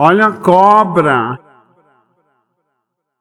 olha a cobra Meme Sound Effect
Category: Reactions Soundboard